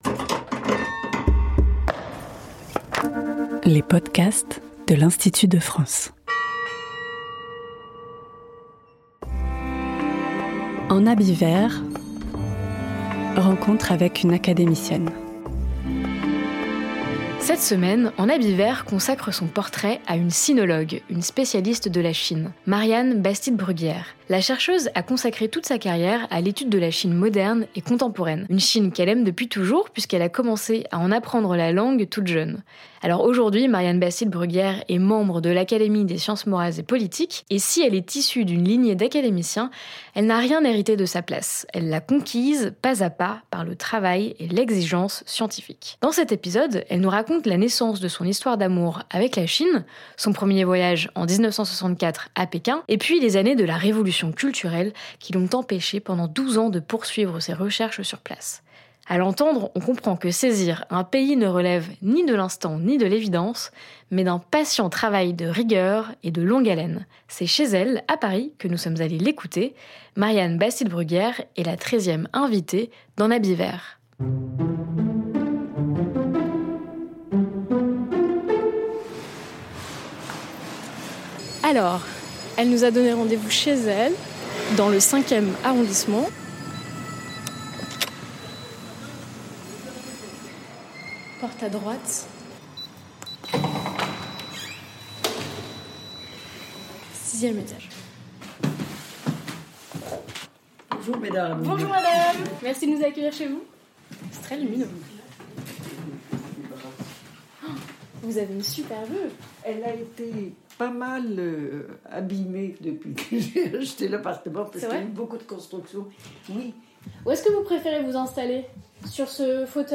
C’est chez elle, à Paris, que nous sommes allés la rencontrer.